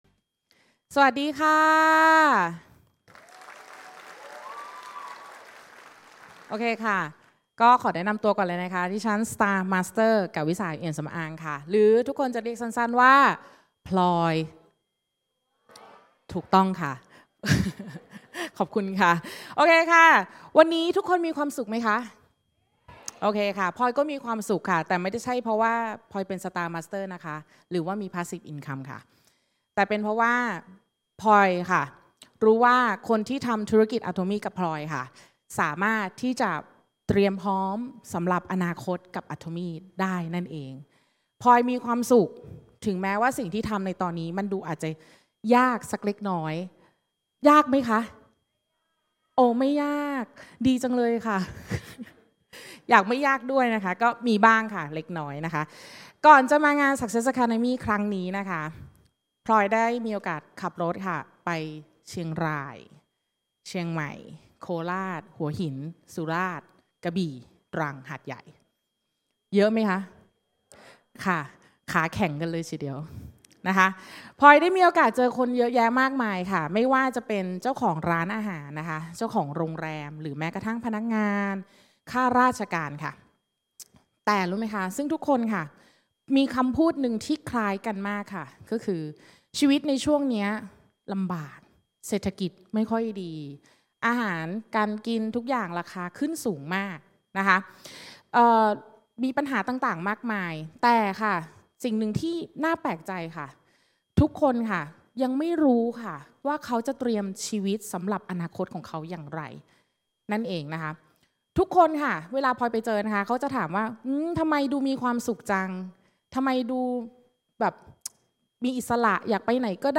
กล่าวต้อนรับ